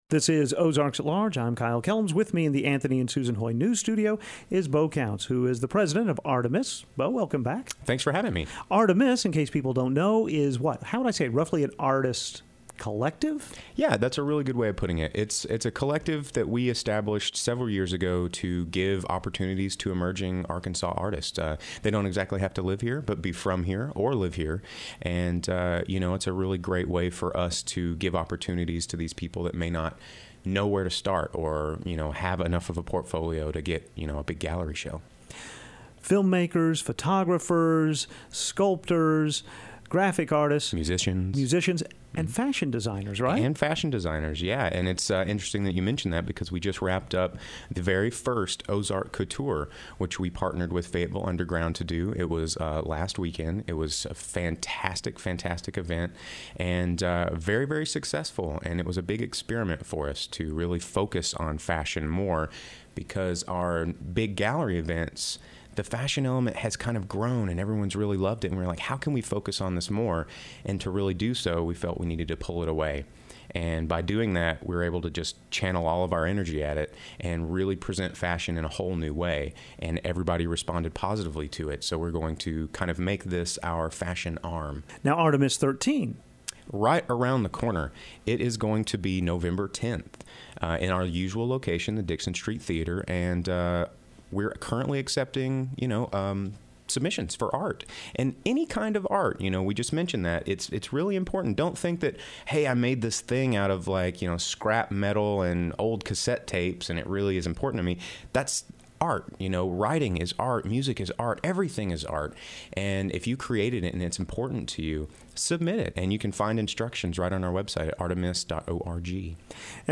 Here is the full interview